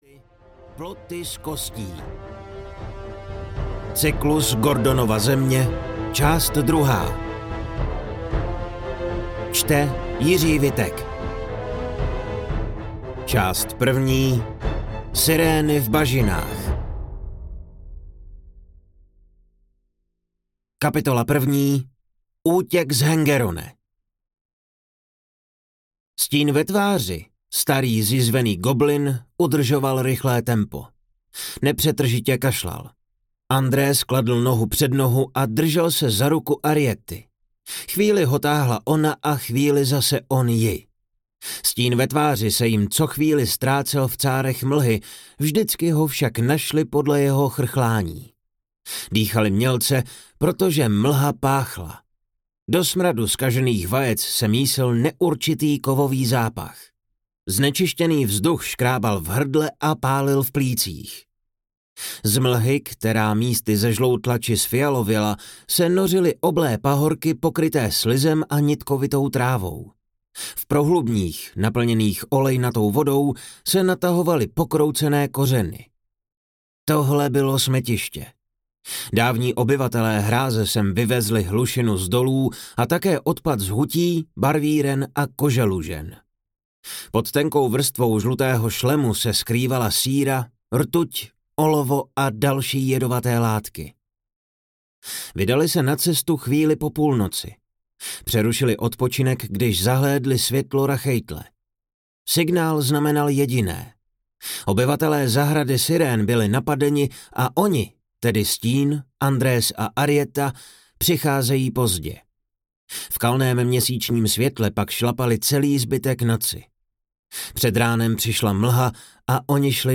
Ploty z kostí audiokniha
Ukázka z knihy